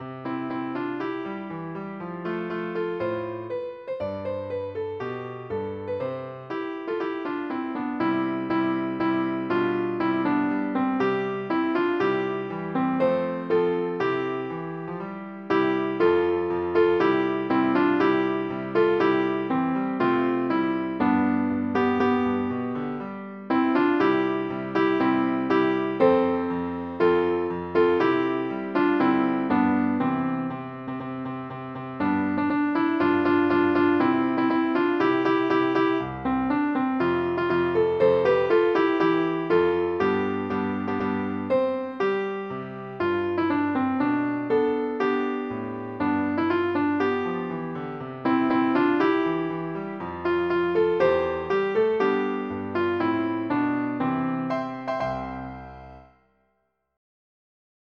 川越小学校 校歌